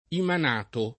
[ iman # to ]